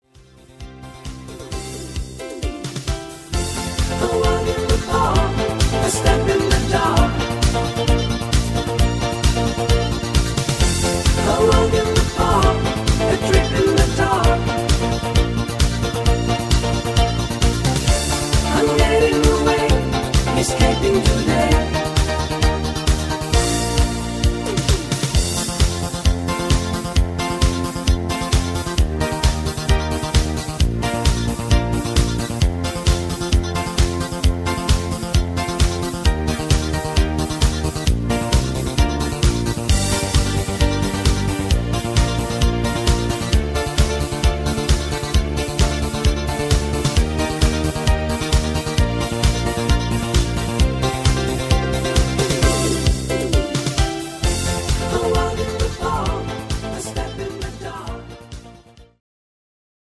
Rhythmus  Disco
Art  Oldies, Englisch